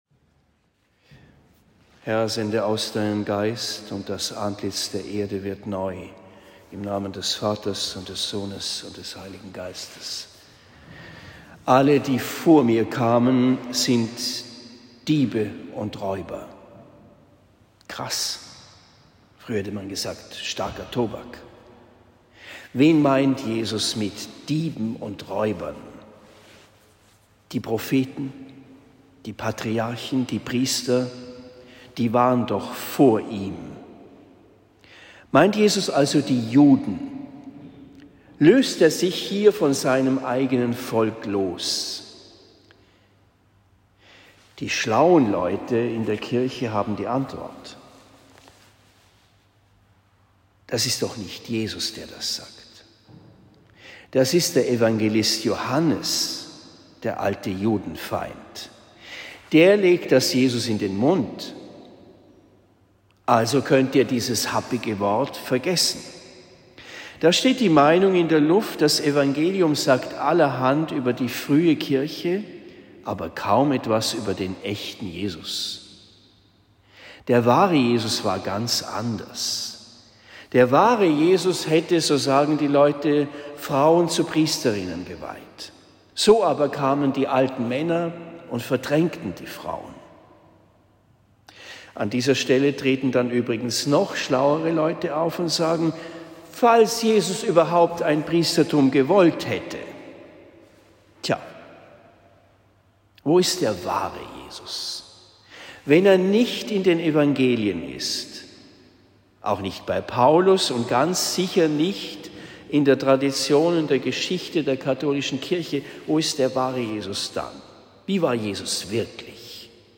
Predigt in Marktheidenfeld am 30. April 2023